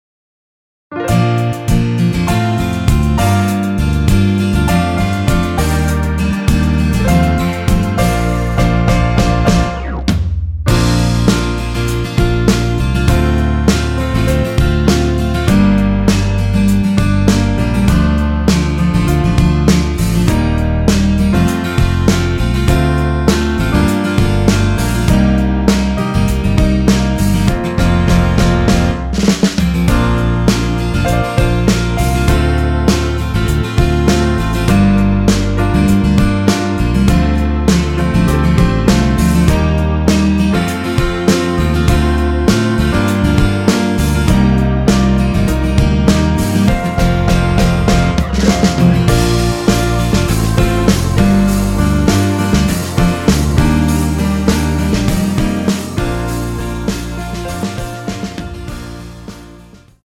원키에서(-1)내린 MR입니다.
Bb
앞부분30초, 뒷부분30초씩 편집해서 올려 드리고 있습니다.